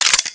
assets/pc/nzp/sounds/weapons/stg/magout.wav at 9ea766f1c2ff1baf68fe27859b7e5b52b329afea